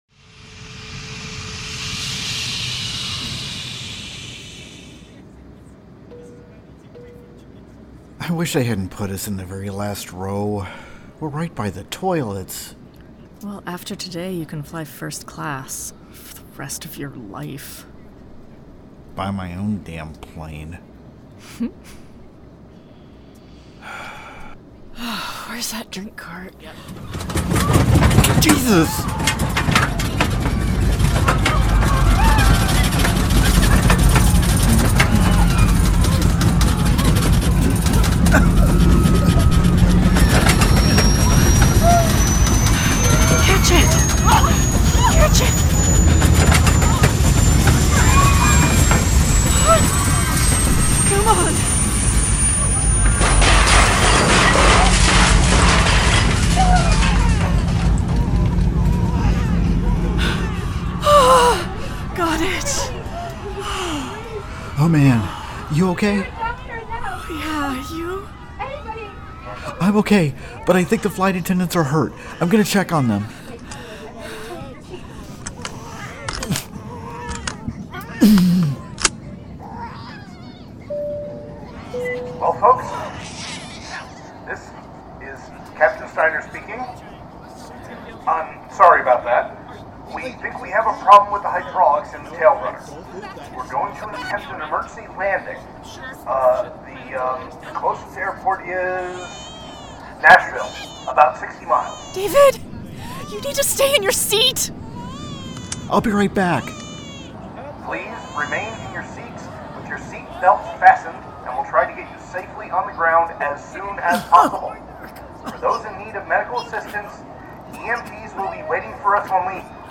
Strangers In Paradise – The Audio Drama – Book 8 – My Other Life – Episode 3 – Two True Freaks
The Ocadecagonagon Theater Group